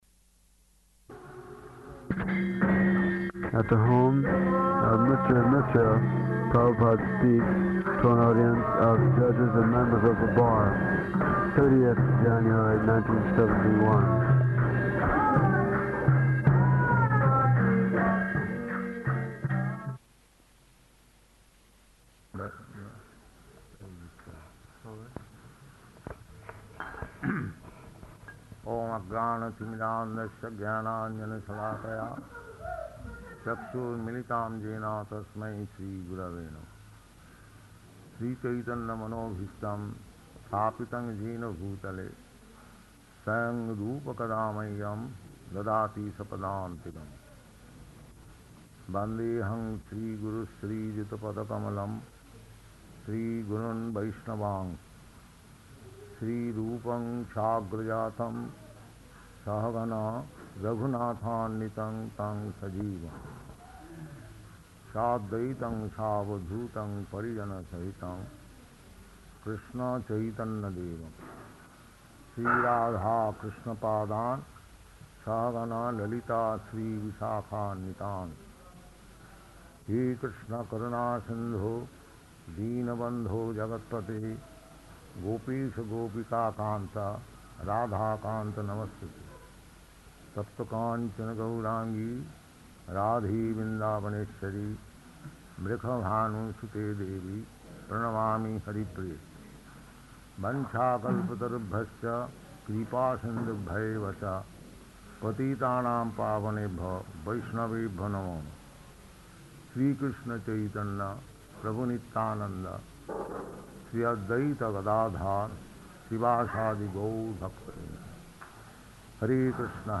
Lecture
Type: Lectures and Addresses
Location: Allahabad